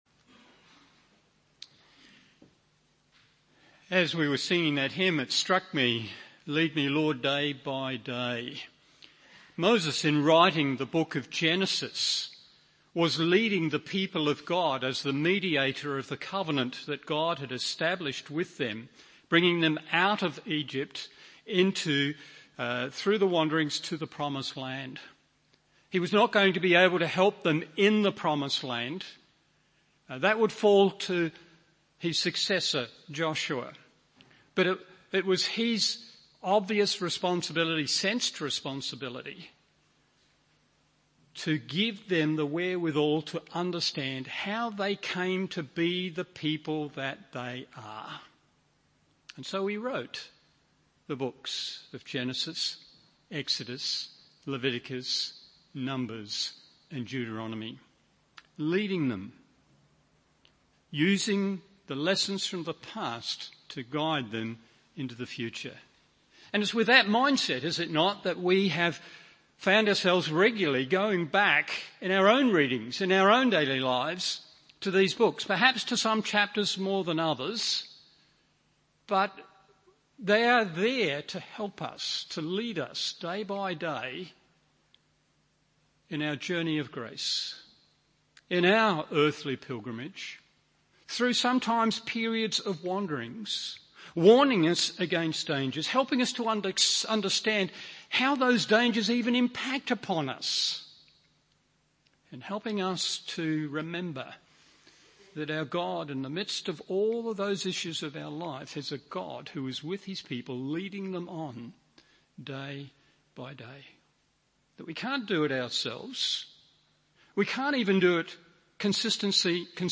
Morning Service Genesis 8:21-22 1.